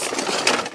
玻璃的声音 " 残骸的摇摆
描述：一个非常短的原始音频mp3录制的残骸，包括玻璃和木头被拖曳。用黑色Sony IC录音机录制。
Tag: 残骸 木材 玻璃 碎片 碎片 洗牌 洗牌